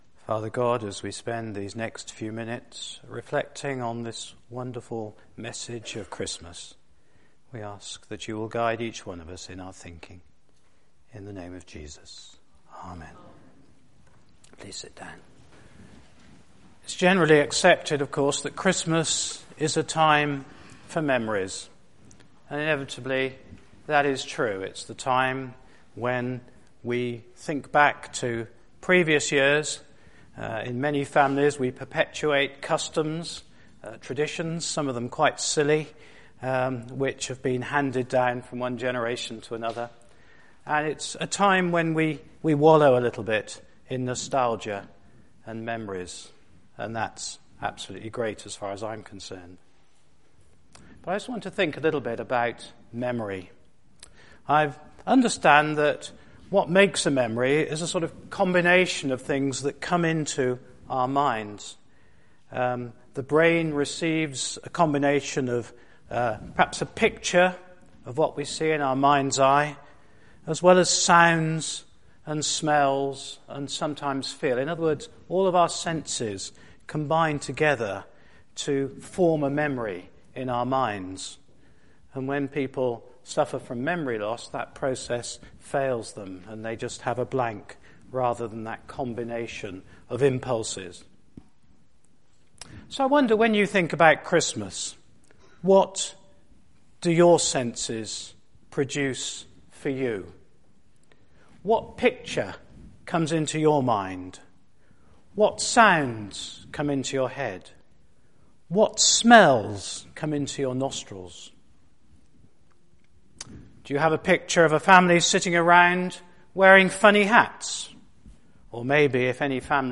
Midnight Mass – A time for memories